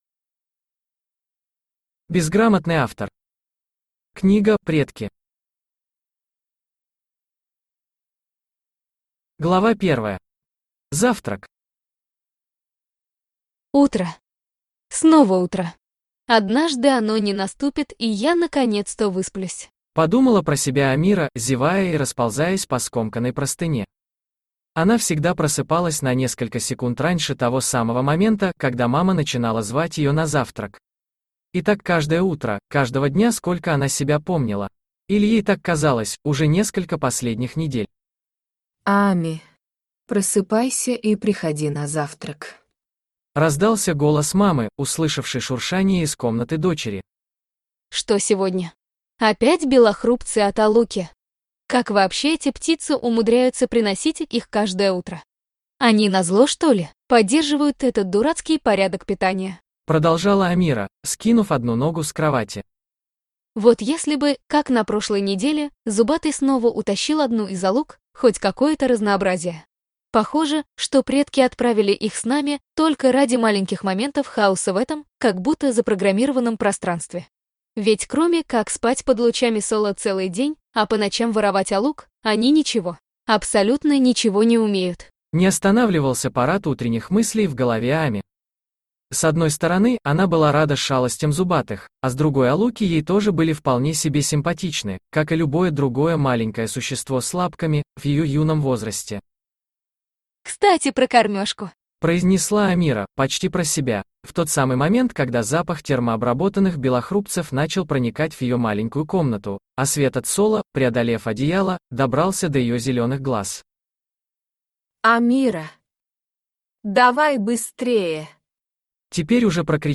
Аудиокнига Предки | Библиотека аудиокниг
Aудиокнига Предки Автор Безграмотный Автор Читает аудиокнигу Авточтец ЛитРес.